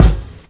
stackkk_kick.wav